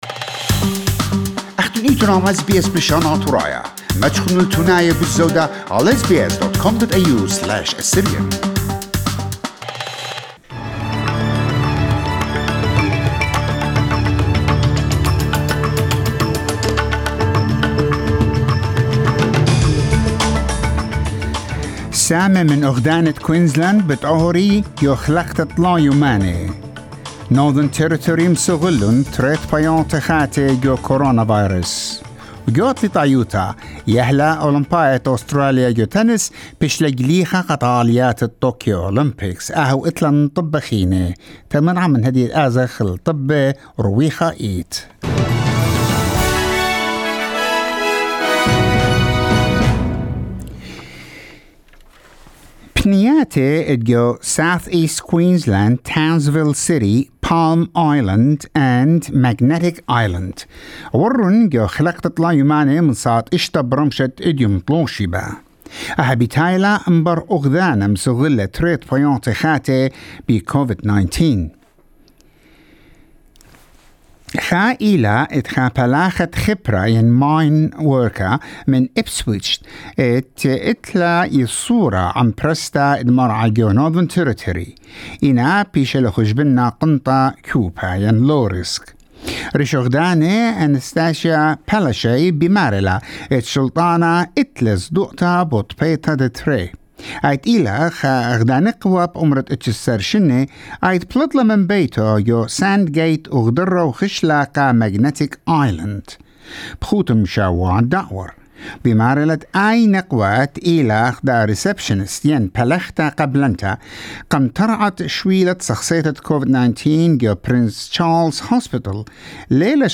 SBS NEWS IN ASSYRIAN 29 JUNE 2021